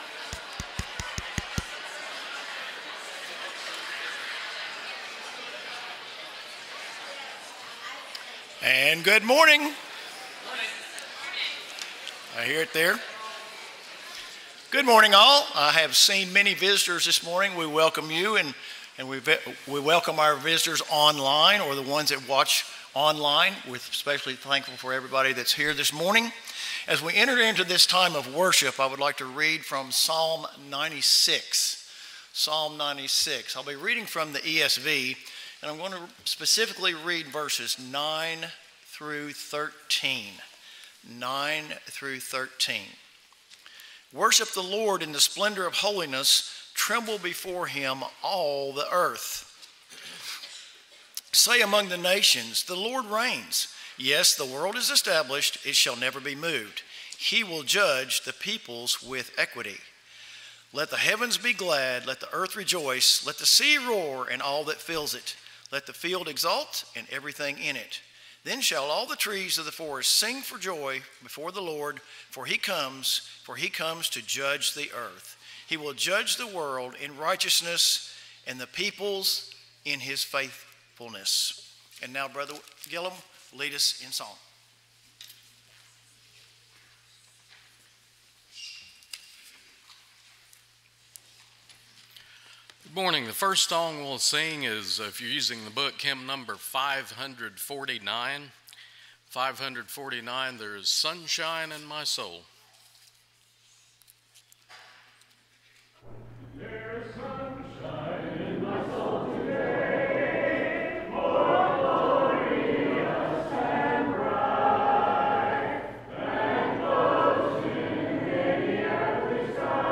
Daniel 2:44 (English Standard Version) Series: Sunday AM Service